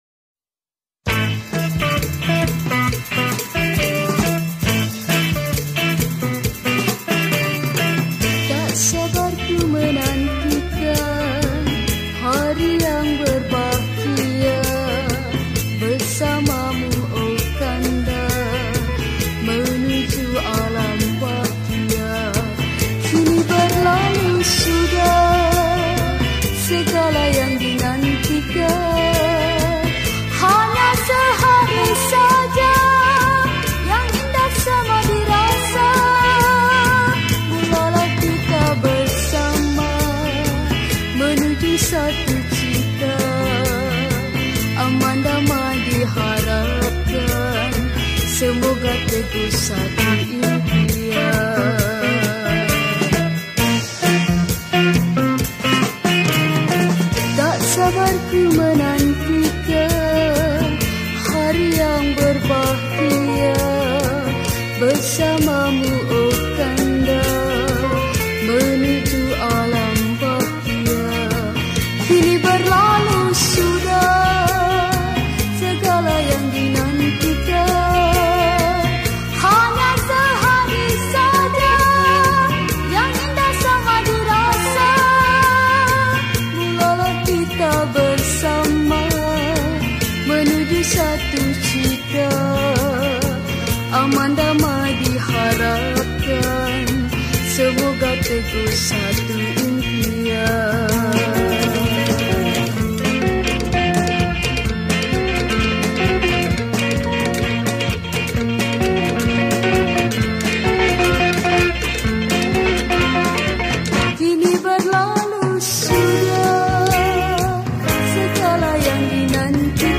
Malay Songs , Pop Yeh Yeh